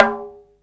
TABLA 11.WAV